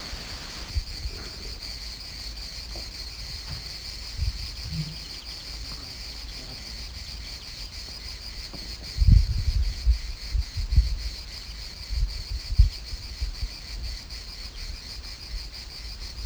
Non-specimen recording: Soundscape Recording Location: Europe: Greece: Silver Island
Recorder: iPhone 6